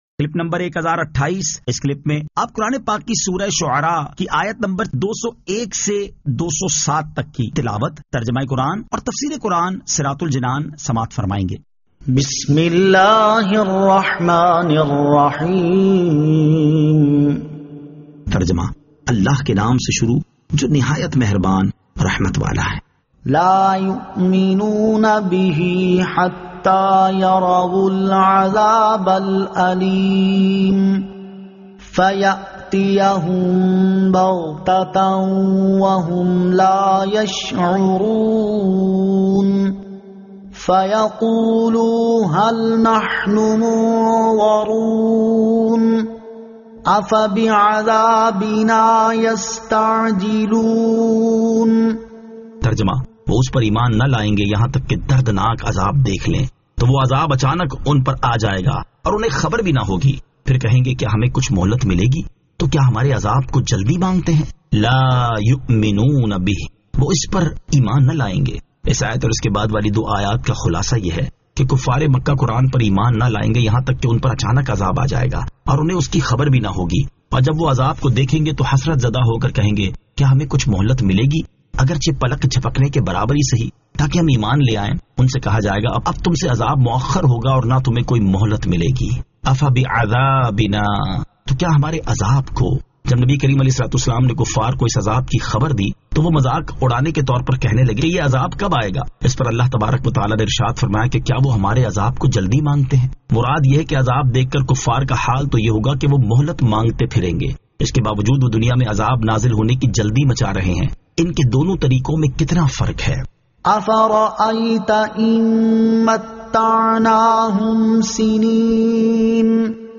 Surah Ash-Shu'ara 201 To 207 Tilawat , Tarjama , Tafseer